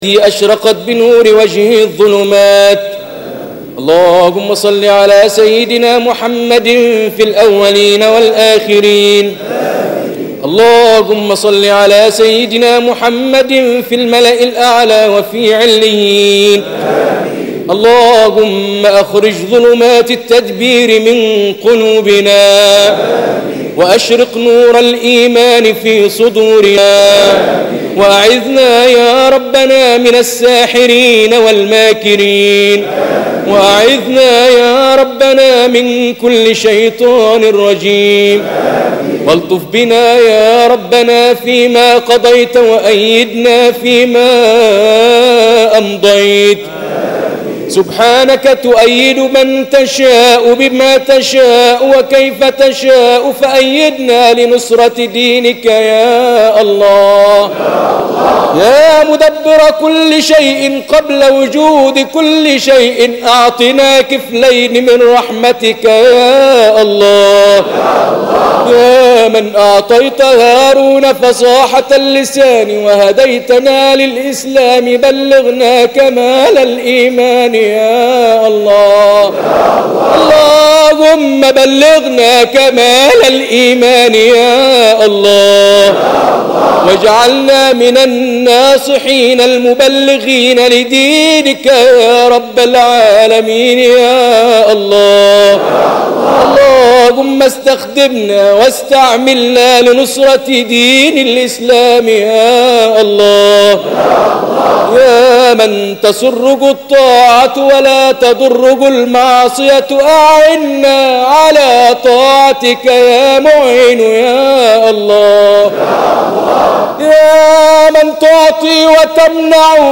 القارئ الطبيب صلاح الجمل، أحد كبار أعلام مدرسة التلاوة المصرية، حفظ القرآن الكريم، وعمره 10 سنوات وحصل على المركز الأول على مستوى الدقهلية، ثم الثانى على الجمهورية، وعمره 11 عاماً، حتى وصل إلى العالمية ليحصل على المركز الأول فى مسابقة كيب تاون بجنوب أفريقيا عام 1995، شاء الله له أن يطوف العالم ممثلًا لمصر من قبل وزارة الأوقاف عام 1987 قارئاً للقرآن، إلى أن طلبته الإذاعة المصرية حتى اجتاز اختباراتها منذ أن بدأ رحلته مع القرآن، وبعد عرض برنامجه الشهير «دعاء الأنبياء»، الذى كان فاتحة الخير، احتل «الجمل» مكانة كبيرة فى قلوب المسلمين من شتى العالم، بسبب صوته العذب، الذى ما إن تسمعه حتى تقشعر الأبدان، ليجعلك تشعر بحلاوة القرآن وتستشعر آياته المحكمات، «الجمل» يرفض التقليد ويعيب على الكثيرين اتخاذهم لهم كمنهج فى تلاواتهم، ويطالب بالاهتمام بالكتاتيب وإعادة دورها، بالإضافة إلى النظر فى تشكيل لجنة اختيار القراء بالإذاعة، حتى تستعيد مصر ريادتها فى عالم التلاوة.